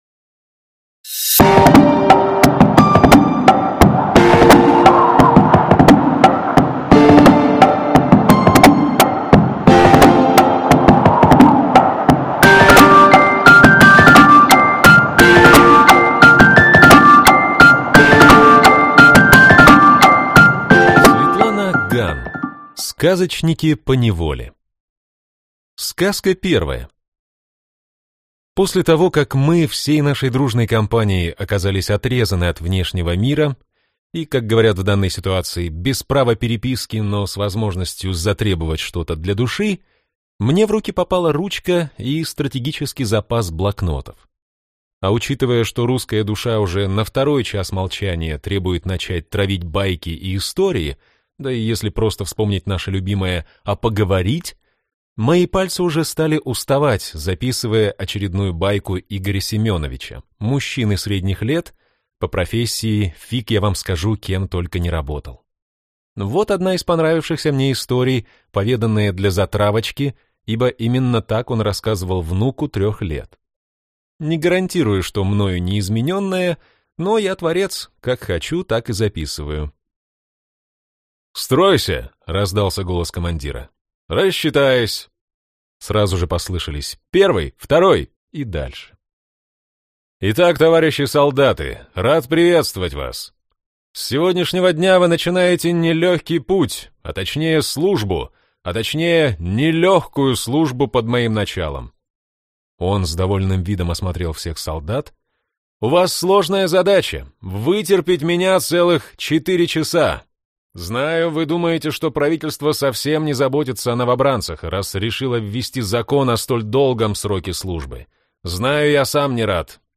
Аудиокнига Сказочники поневоле | Библиотека аудиокниг
Прослушать и бесплатно скачать фрагмент аудиокниги